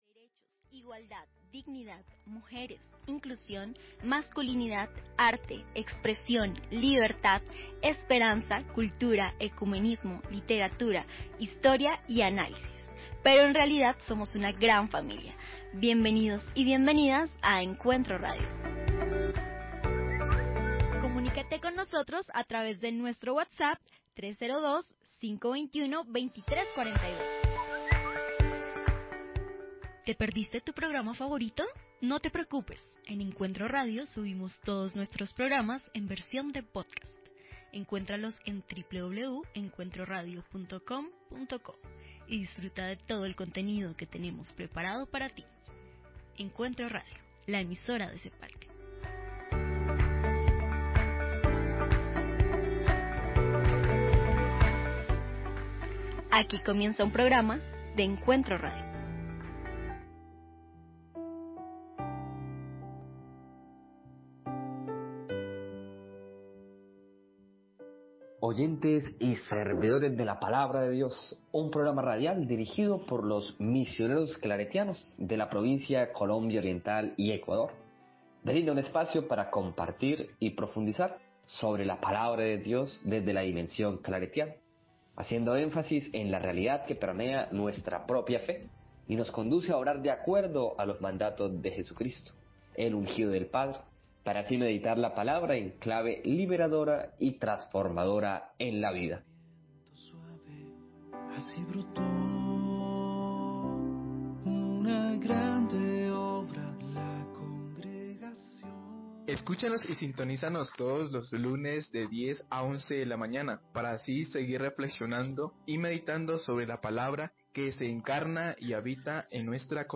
Canciones, imitaciones, actuaciones y mucha risa es lo que se puede encontrar en este conato de magazine político.